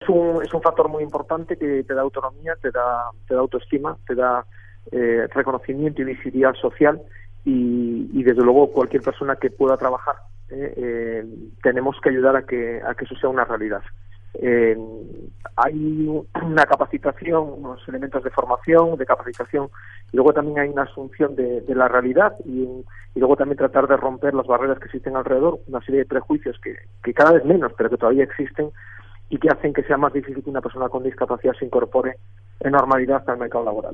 en declaraciones a Radio Nacional los fundamentos de este reconocimiento para la Organización